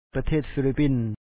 pathèet filipìn The Philipines